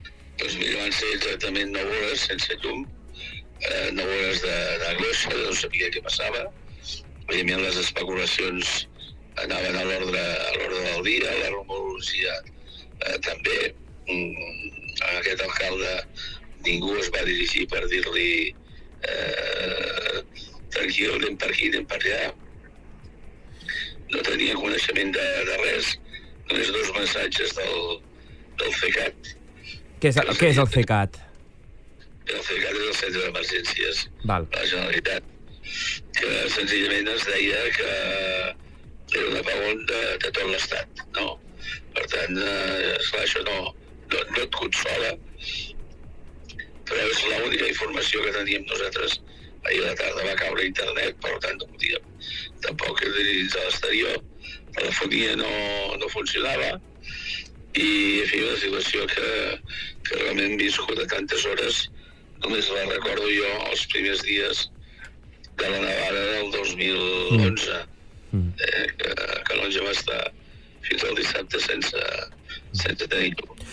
Al Supermatí hem fet un programa especial per poder recollir els testimonis d'alcaldes, ciutadans i empresaris de la comarca per veure com van afrontar les hores sense llum d'aquest dilluns